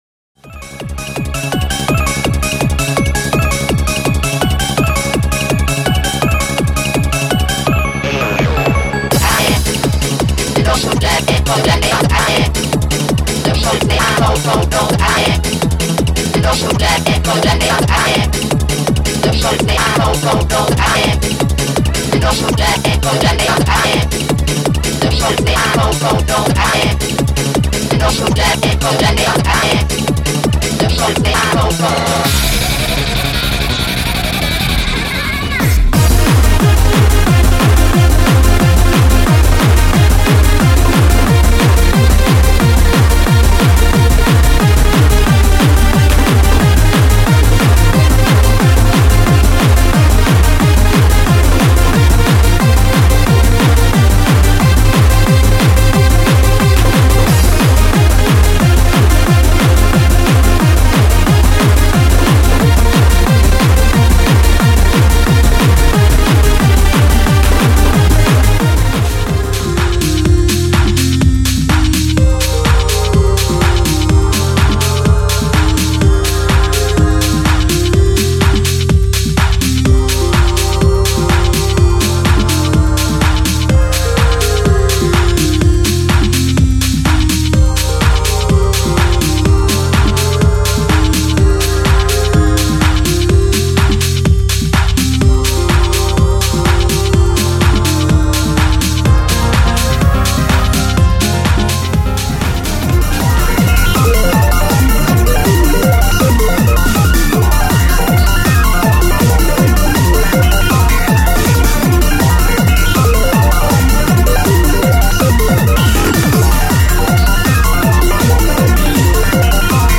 約30秒ずつのクロスフェードです。